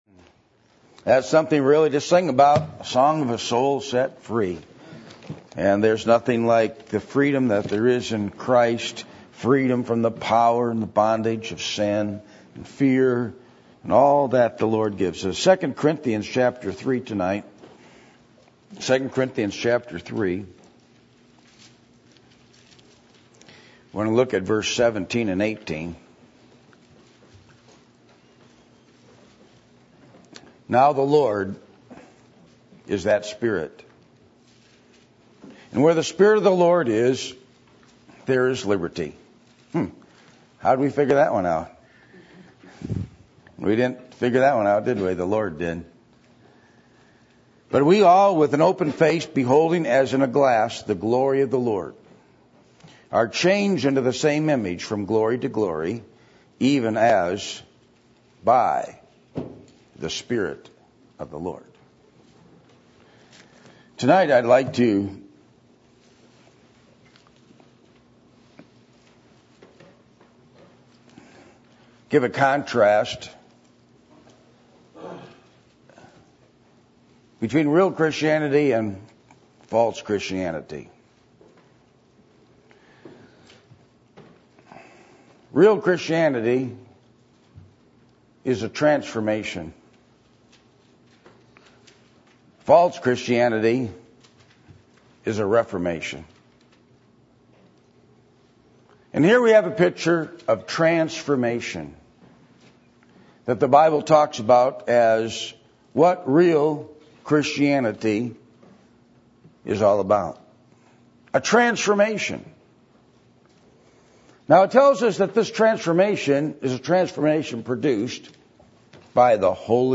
2 Corinthians 3:17-18 Service Type: Sunday Evening %todo_render% « Are You Going Forward Spiritually